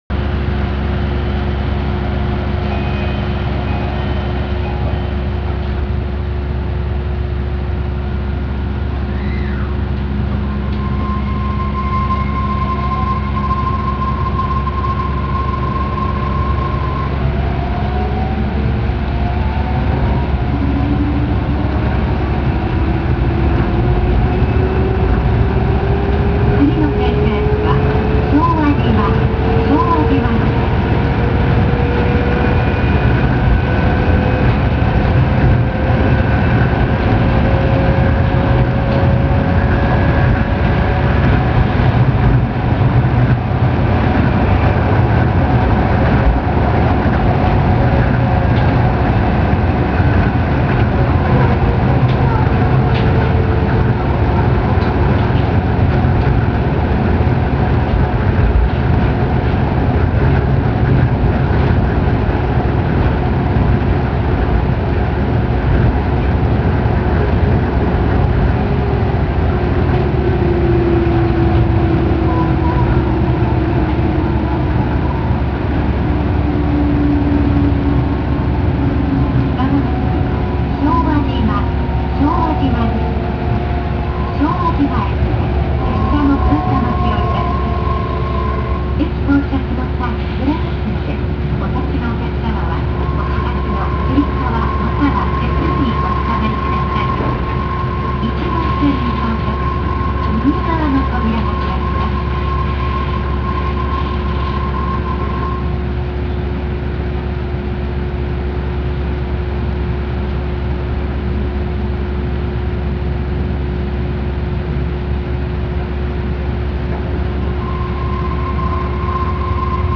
・10000形走行音
【羽田空港線】流通センター→昭和島（2分17秒：745KB）
近年の日立製IGBTインバータを採用しており、JR東日本のE531系、東武鉄道の60000系などに類似した走行音となっています（非同期音が同じ）。モーター音自体は静かですが、やはりモノレールという特殊な路線である以上ある程度の揺れは感じます。
車内放送の声は今まで通りですが、ドアチャイムはJR東日本タイプの物に変更されました。